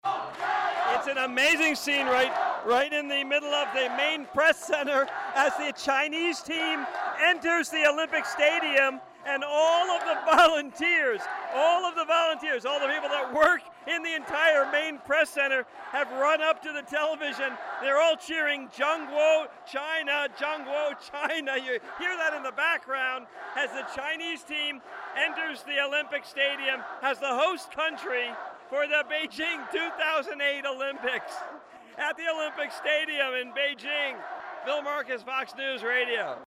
2008 Beijing Olympics, China, Fox News Radio, Selected Reports:
Friday, August 8, 2008: Watching the opening ceremonies on TV workers at the press center erupt in chants and cheers